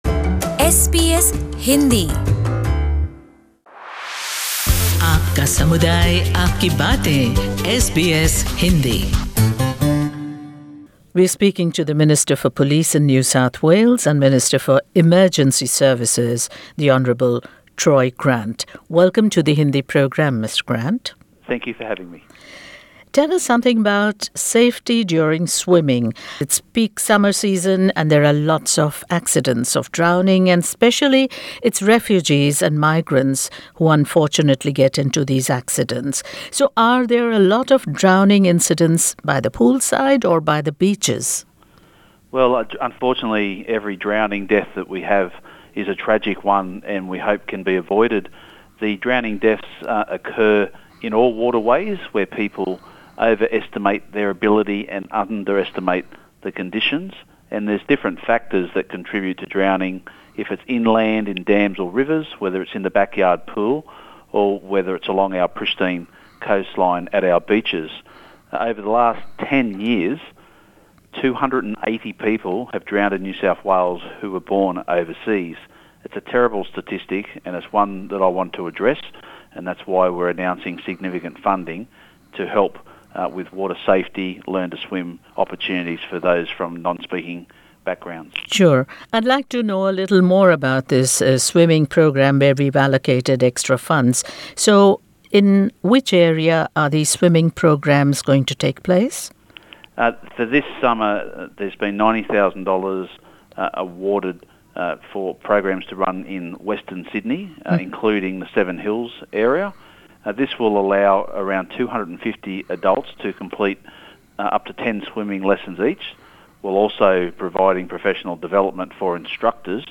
Troy Grant, NSW Minister for police and Emergency Services Source: Supplied In this exclusive interview the Hon. Troy Grant, Minister for Police and Emergency Services tells us more about the program and provides tips on water safety.